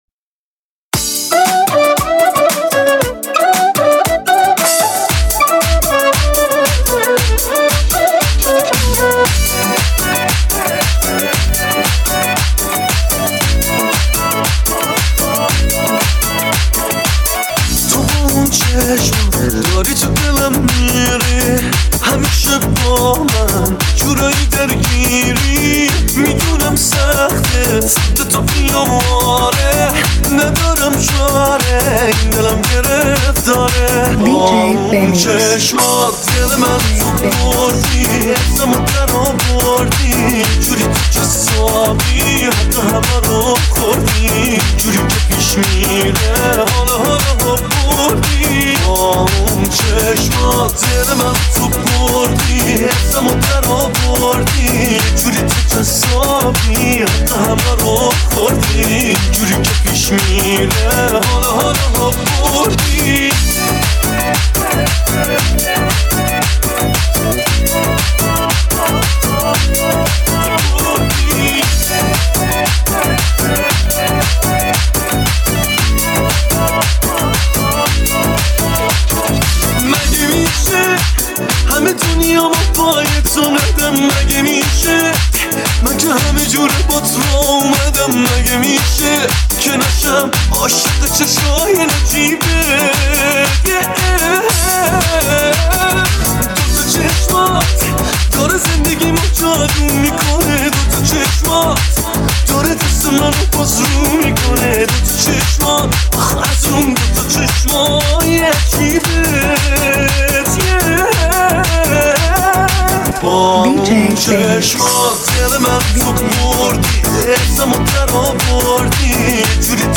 تجربه‌ای پرانرژی از موسیقی!
یک تنظیم فوق‌العاده و ریتمیک